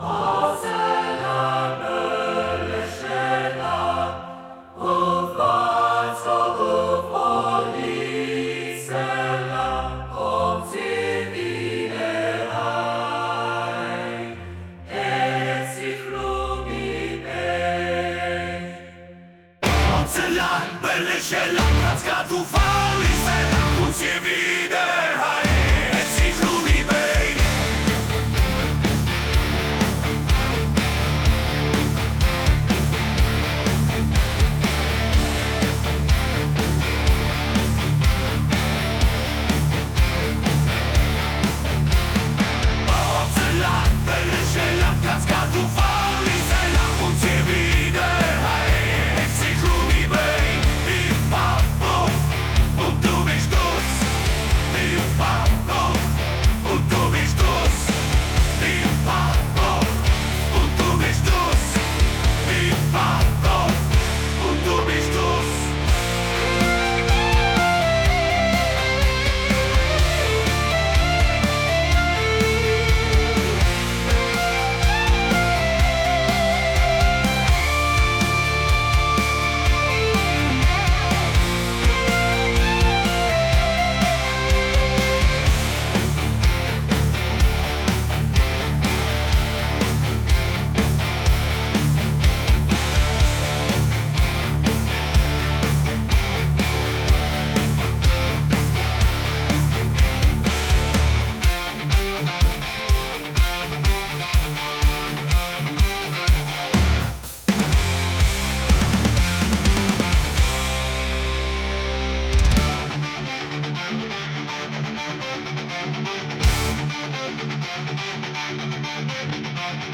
localsearch: A suon di rock con l’IA - localsearch
A volte basta creare per gioco una versione rock dai versi di una canzone popolare in svizzero tedesco per capire come questa risorsa possa davvero rivoluzionare il futuro.